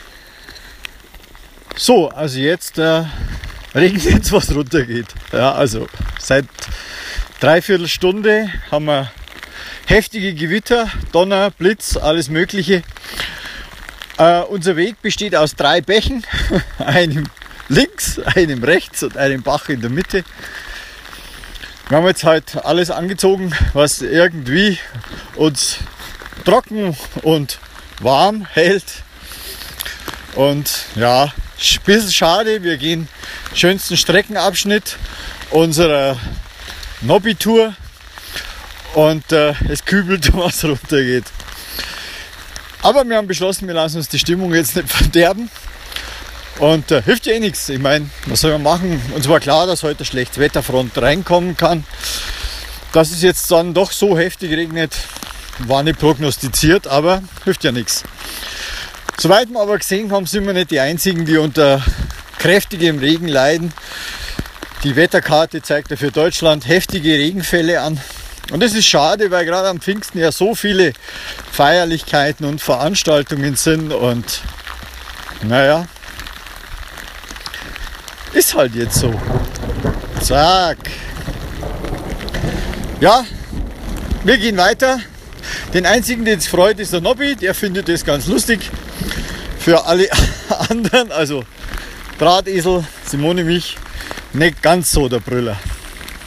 Es kübelt